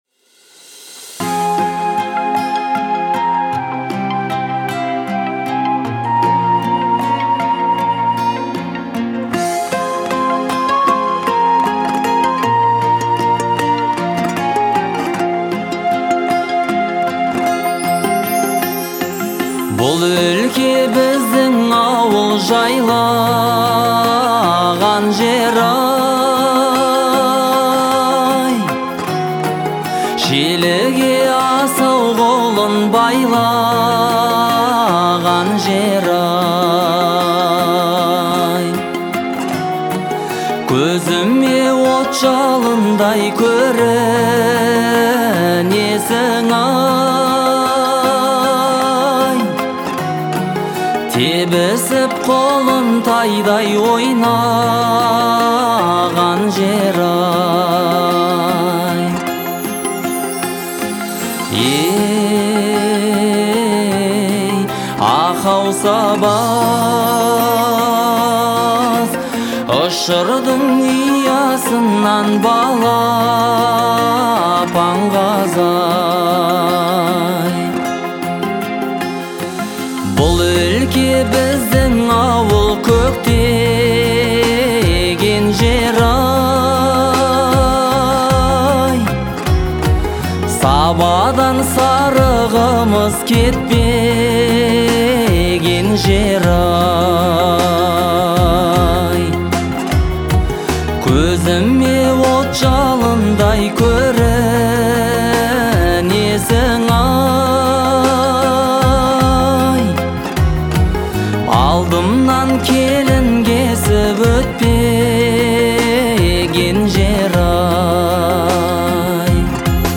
это яркая и мелодичная песня в жанре поп